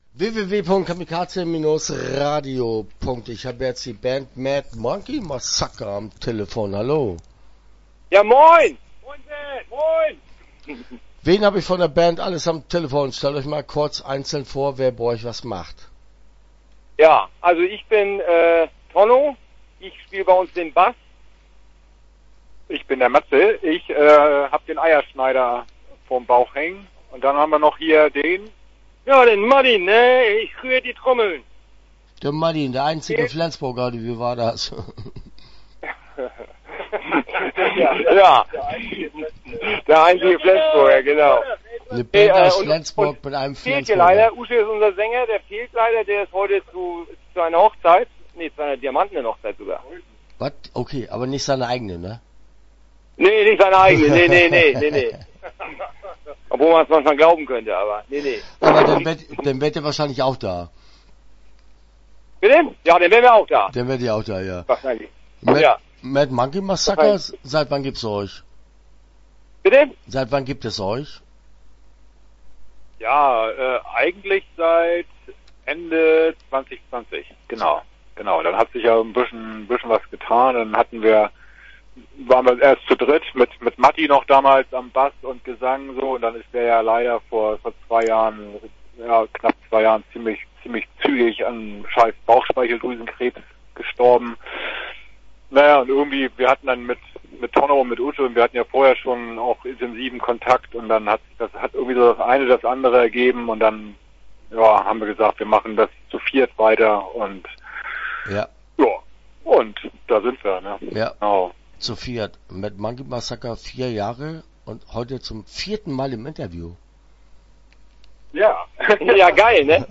Mad Monkey Massacre - Interview Teil 1 (12:37)